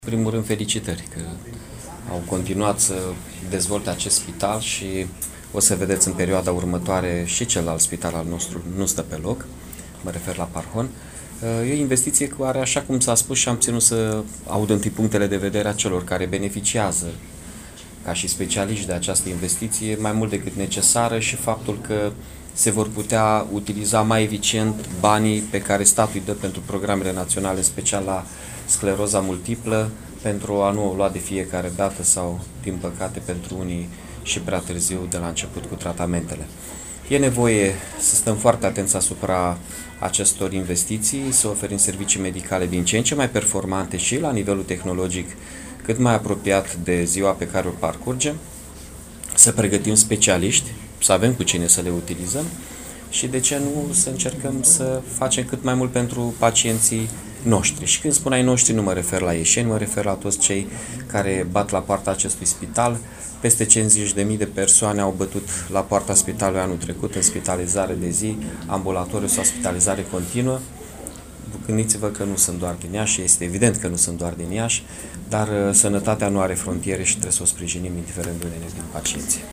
Declarația primarului municipiului mIași, Mihai Chirica